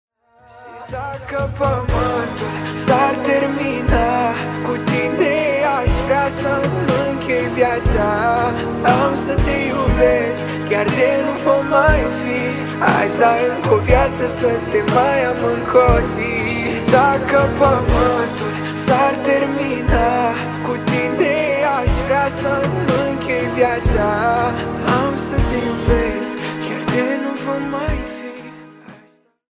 Categorie: Pop